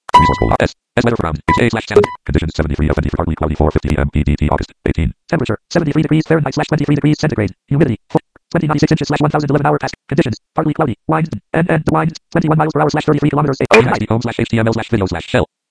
• The text-to-speech is produced by the IBM ViaVoice engine, this can now be purchased from a couple of sources in the US and Europe.
These demos are primarily intended to show the efficiency of the environment, and slowing down the speech would detract significantly from that goal.
• Careful listeners will notice some jitter/clipping in the audio --- this appears to be an artifact of using recordmydesktop that I was unable to eliminate.
Notice that you also hear auditory icons to indicate that a prompt input area just opened.
This is in a lower-pitched (deeper) voice since it was generated from the title of the corresponding RSS item.
• You hear auditory icon close-object to indicate the weather forecast being dismissed.